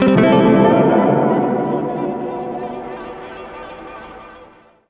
GUITARFX14-L.wav